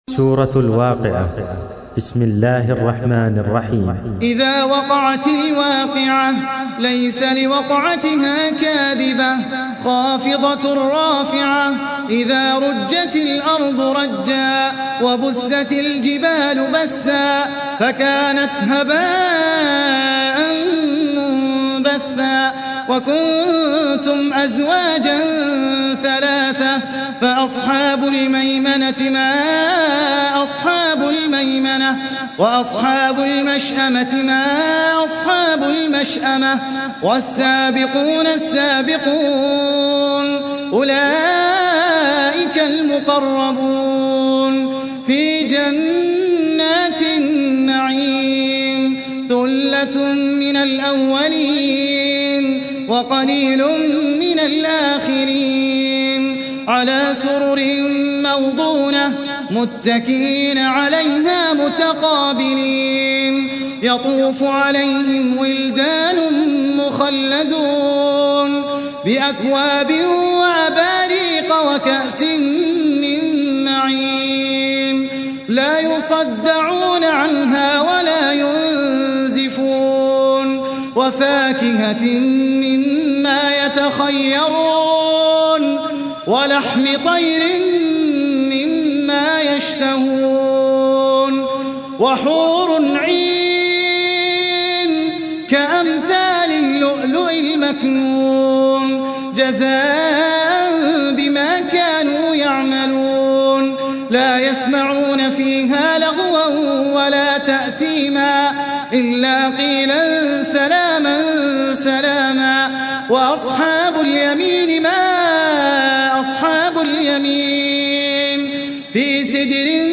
سورة الواقعة | القارئ أحمد العجمي